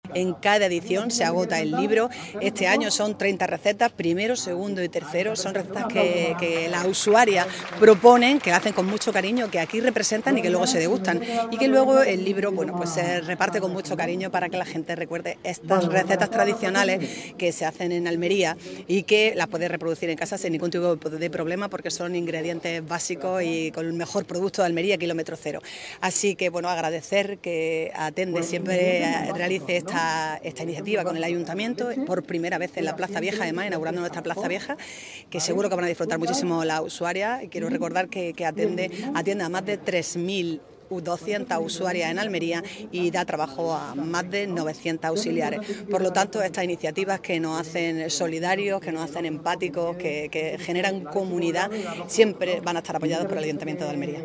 ALCALDESA-SABOREANDO-RECUERDOS-ALMERIA.mp3